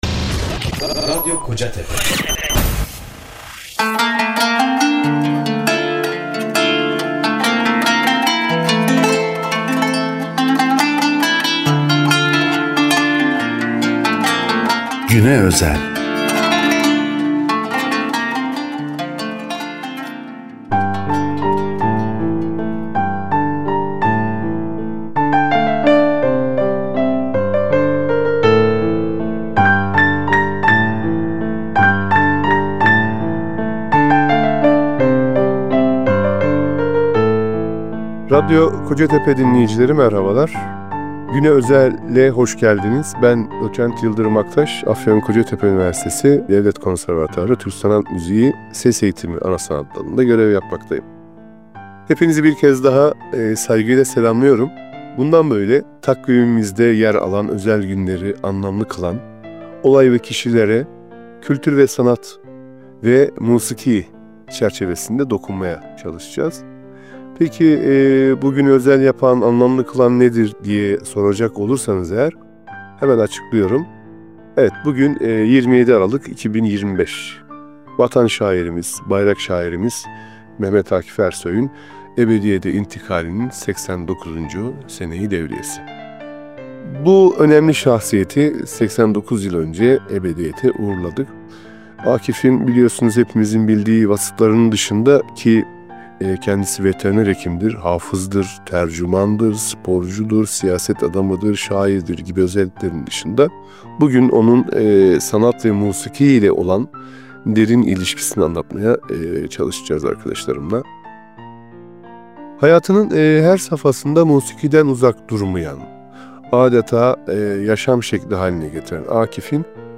Güne Özel – “Safahat’tan Besteler” isimli Radyo Söyleşi/Müzik Programı 27 Aralık 2025 Perşembe günü saat 14:00’te Radyo Kocatepe’de yayımlandı.